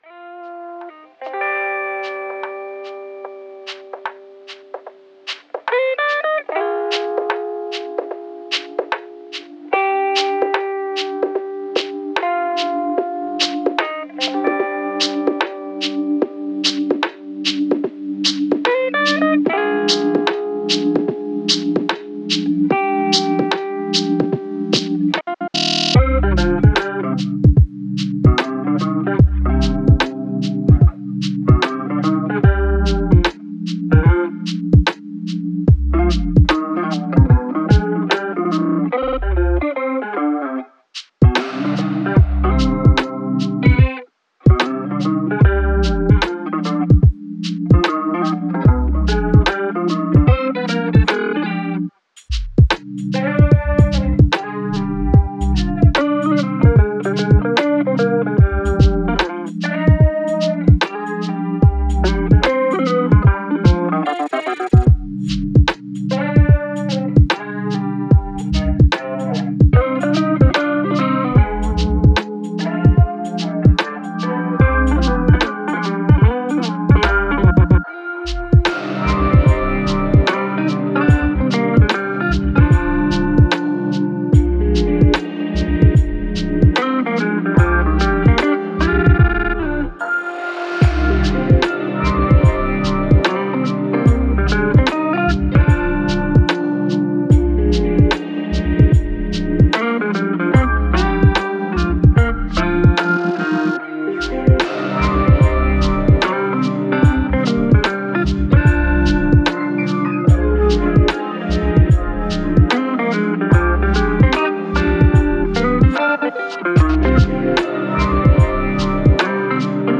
Genre: chillhop, triphop, jazz.